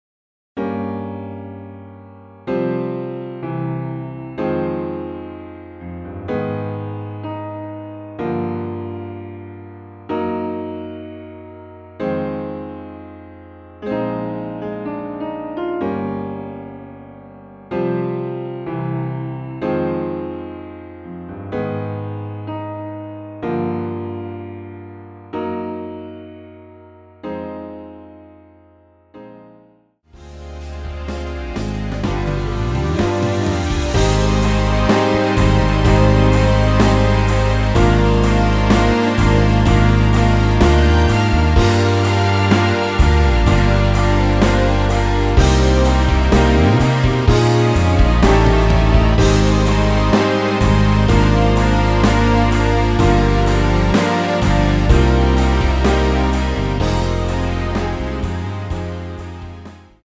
Eb
앞부분30초, 뒷부분30초씩 편집해서 올려 드리고 있습니다.